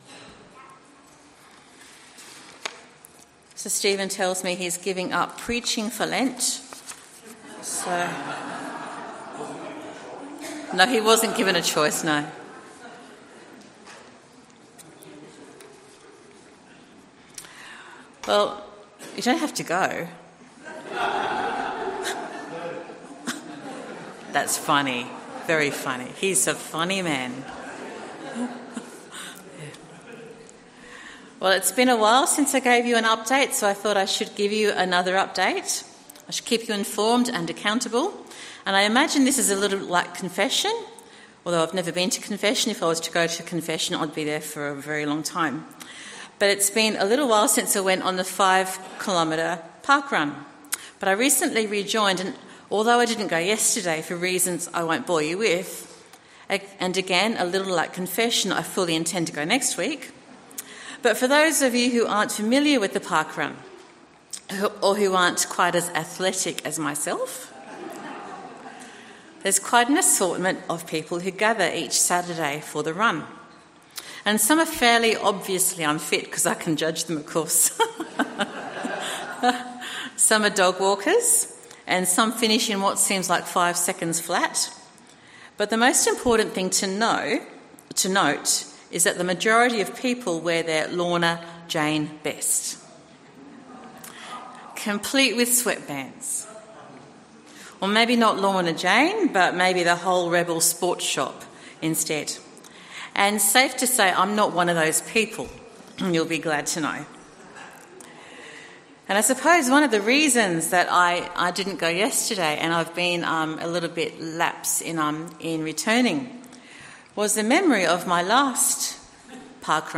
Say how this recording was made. Sermon from the 10AM meeting at Newcastle Worship & Community Centre of The Salvation Army. The related Bible reading is Romans 12:3,5.